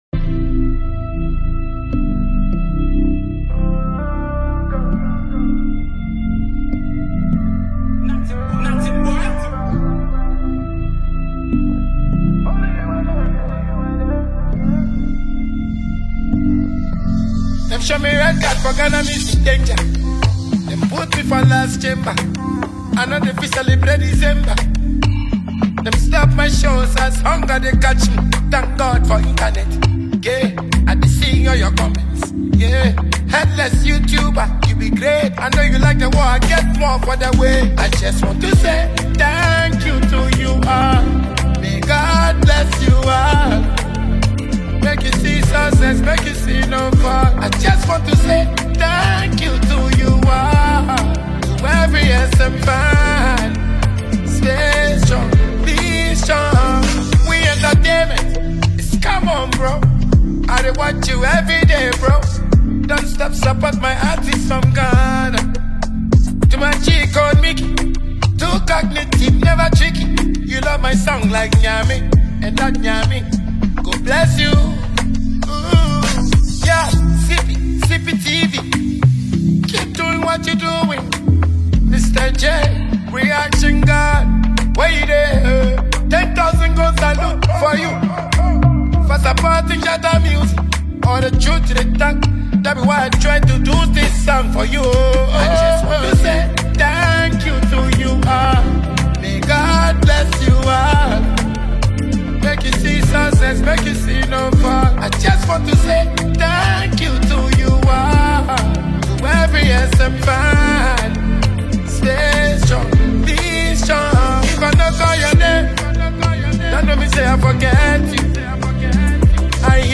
is a catchy dancehall single